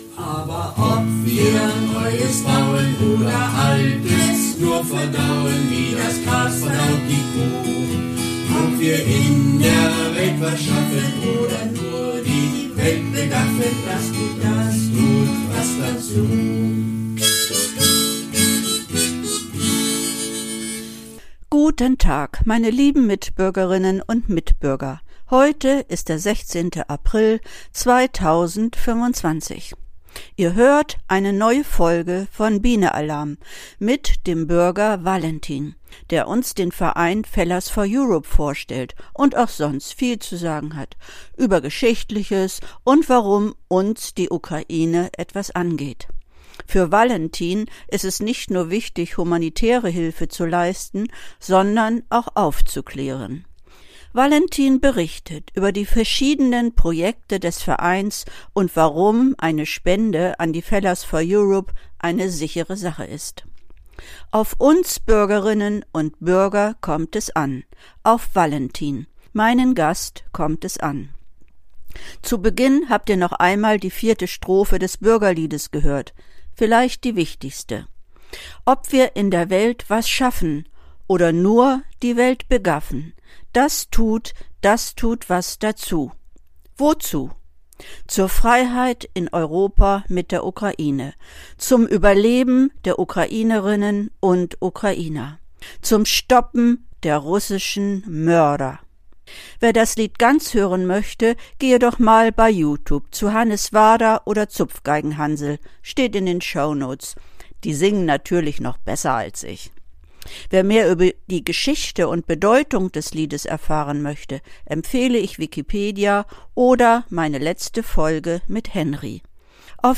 Bürger tun was dazu: Interview